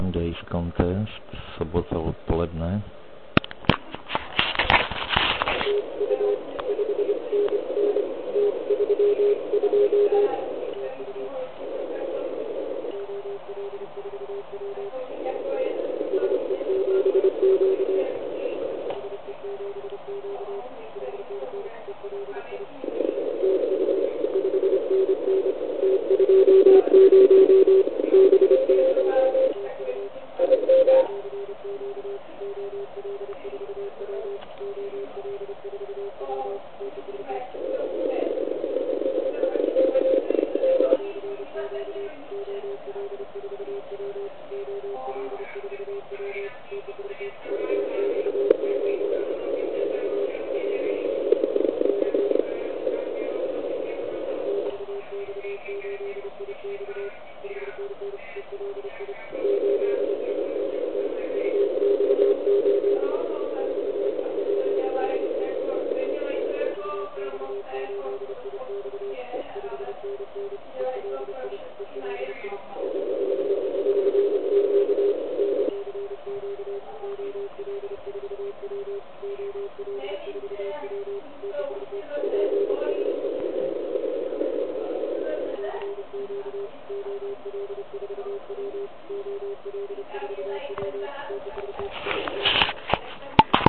Připravil jsem pro naše žáky tedy 3 nahrávky z pásma 80m, kde jsem jel v kategorii LP (do 100W).
Zde si všimněte "soundu" TS480 přes CW filtr 270Hz. Vypnuté DSP, stažená citlivost, AVC Fast.